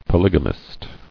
[po·lyg·a·mist]